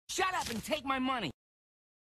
twitch-follower-sub-donate-sound-effect-alert-shut-up-and-take-my-money-2.mp3